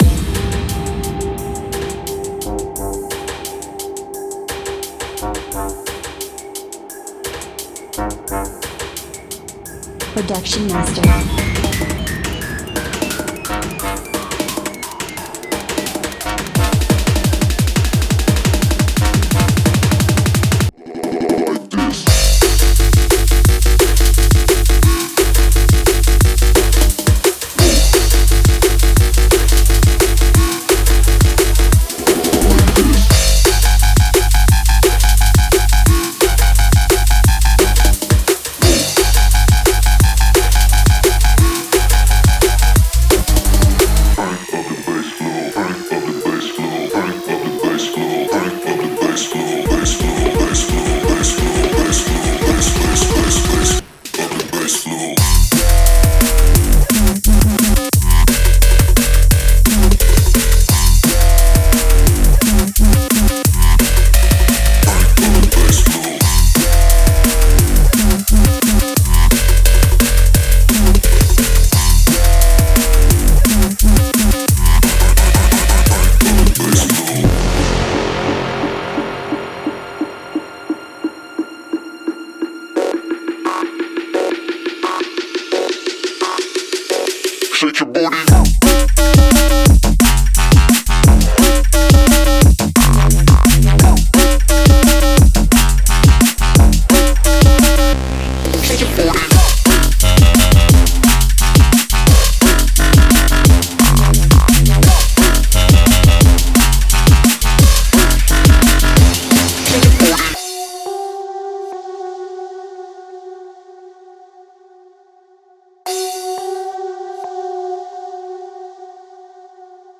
潜入混有沉重鼓声的令人讨厌的低音世界，并消灭所有人群。
重低音：利用这些凶猛的低音切入每个声音系统。
扎实的踢脚，粉碎的圈套器和紧身的帽子经过精心设计，可以完美地填满您的作品，并大肆宣传。
侵略性的Xfer血清预设：这些绝对野蛮的低音和残酷的导线破坏您的扬声器。